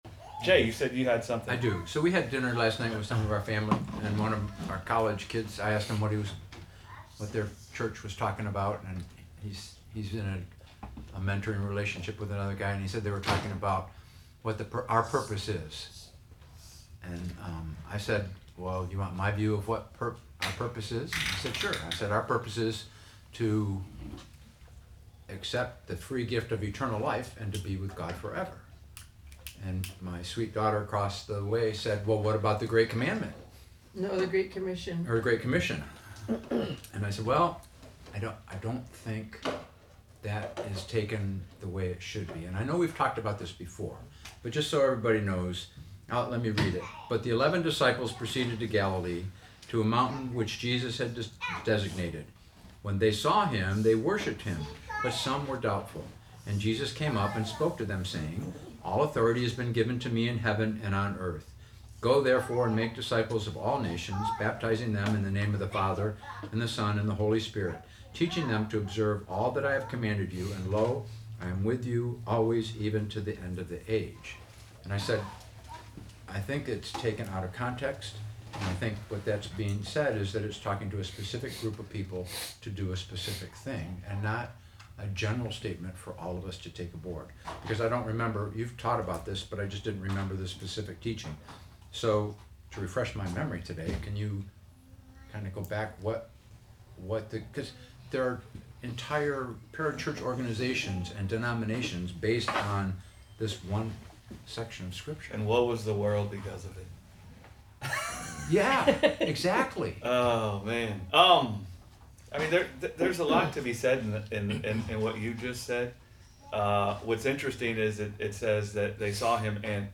Sunday Bible Study: Raising Up Fathers - Gospel Revolution Church